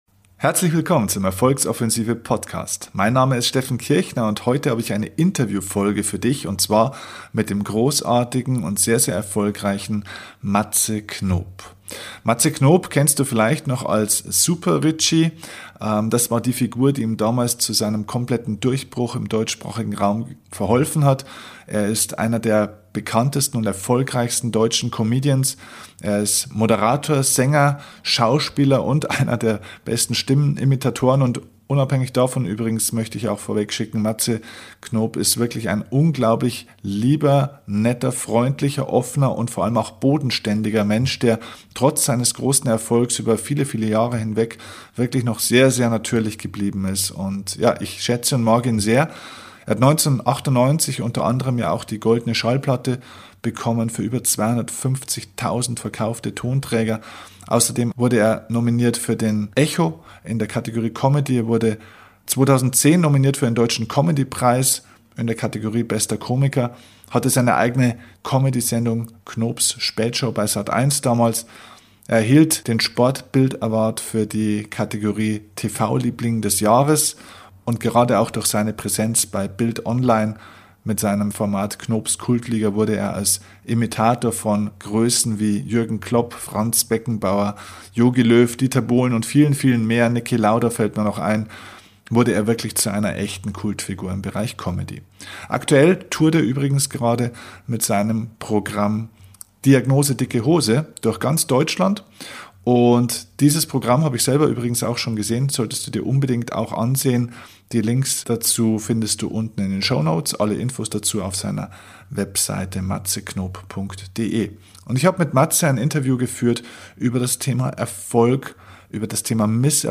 Beschreibung vor 8 Jahren #91 Erfolg muss man aushalten können - Interview mit Comedian Matze Knop Matze Knop zählt zu den bekanntesten und erfolgreichsten Comedians und Entertainern Deutschlands. In diesem tollen Interview sprach ich mit dem extrem sympathischen TV-Star über Wege zum Erfolg, die Verarbeitung von Misserfolgen und den Umgang mit Hatern.